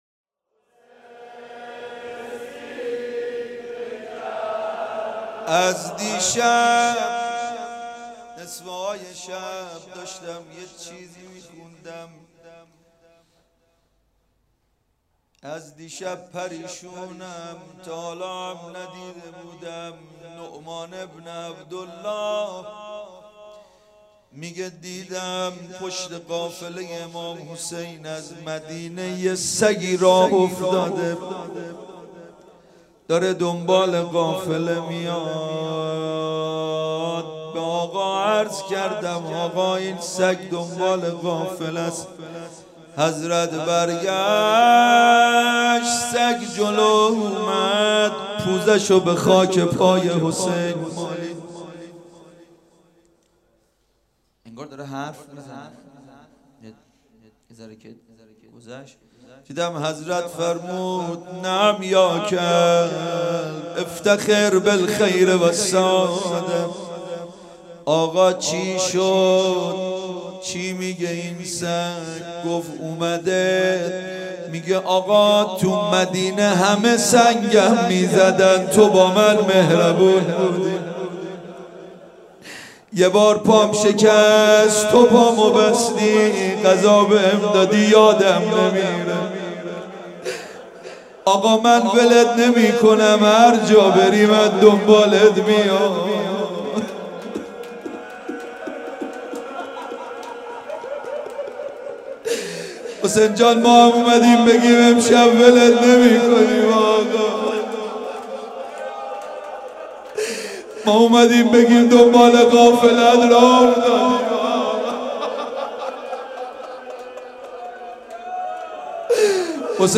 روضه امام حسین (ع)